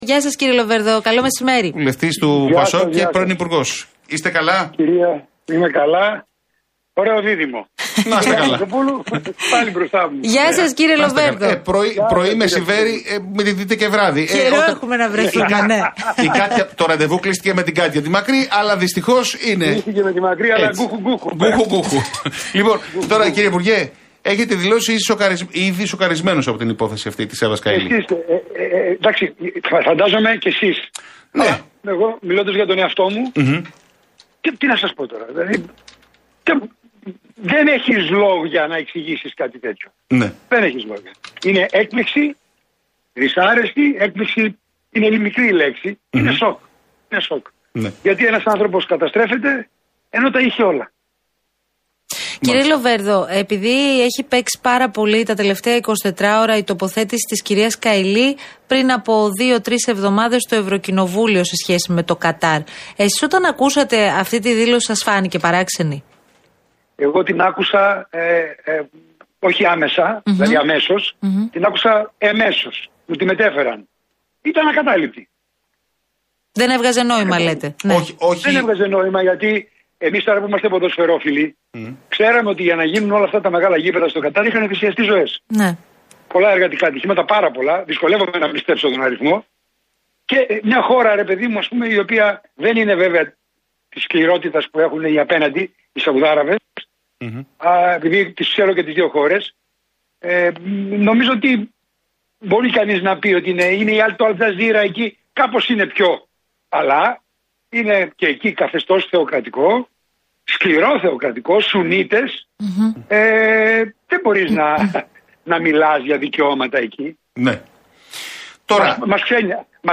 Λοβέρδος στον Realfm 97.8: Κάποιοι ζητούσαν χάρες από την Εύα Καϊλή και τώρα υψώνουν το δάχτυλο